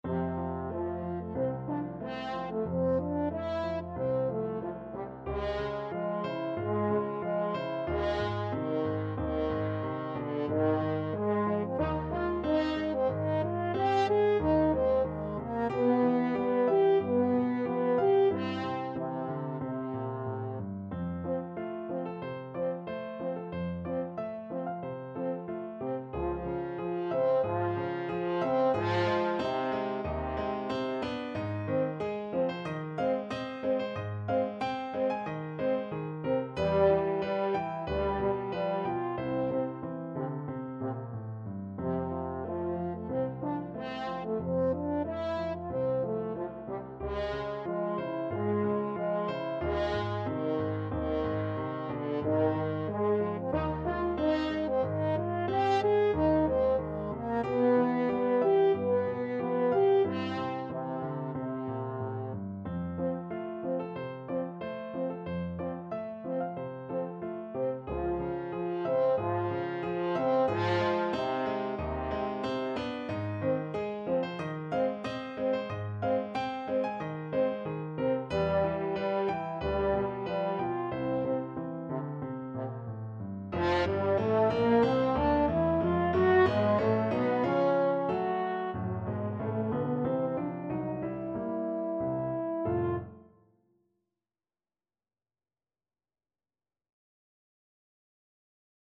Andantino =92 (View more music marked Andantino)
4/4 (View more 4/4 Music)
Classical (View more Classical French Horn Music)